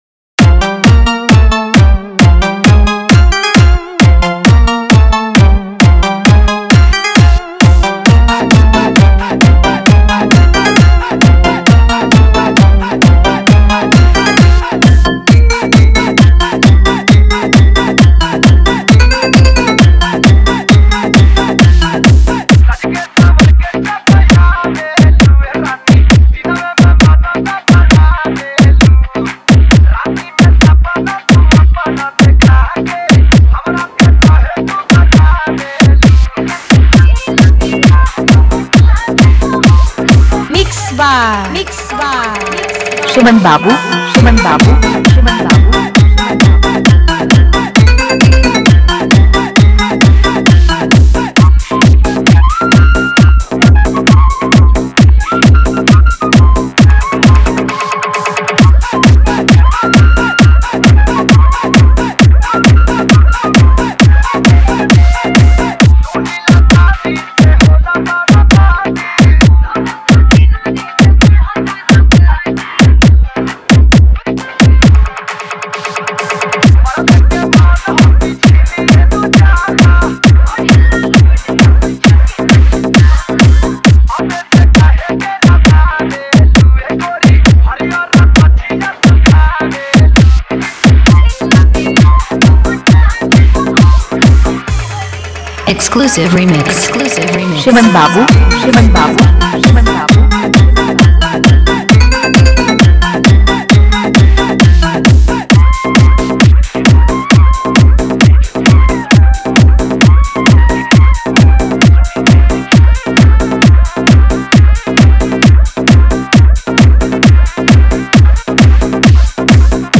Upcoming: - 2025 Special Bhojpuri EDM Tahalka Song